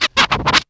3 SCRATCH.wav